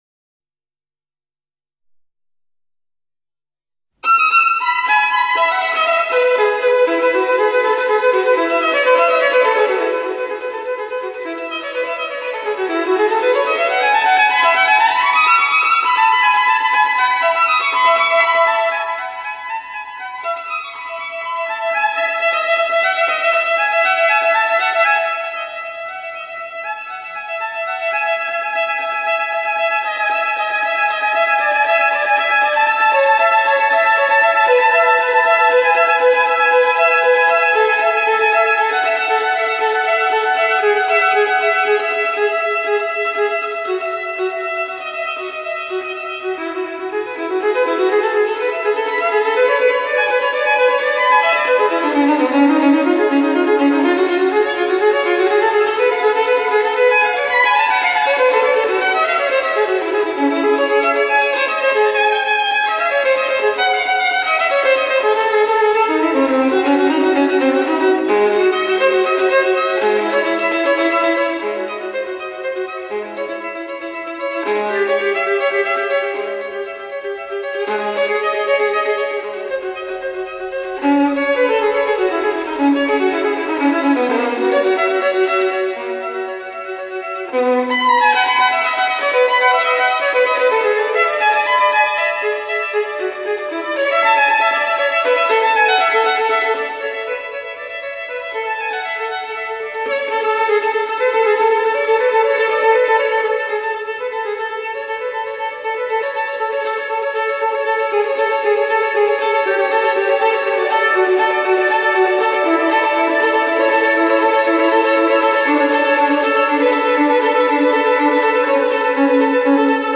(巴哈/無伴奏小提琴組曲)